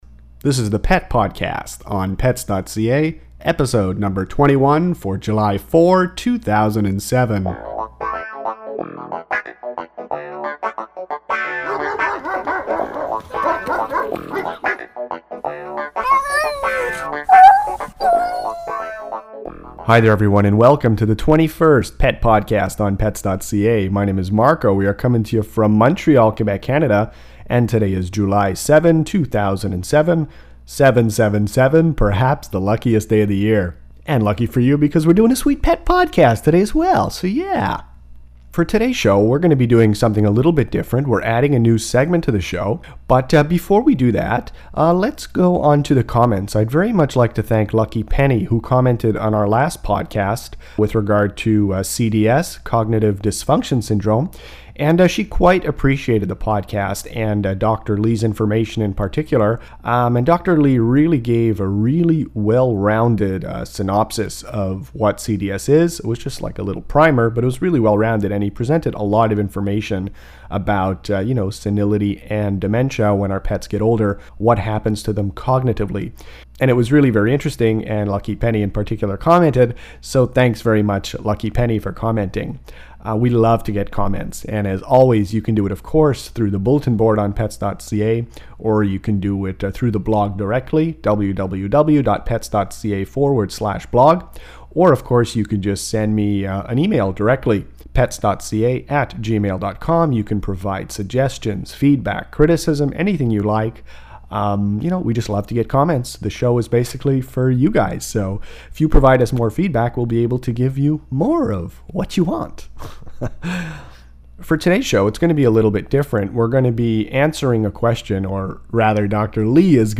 Bee stings in cats and dogs – Pet podcast #21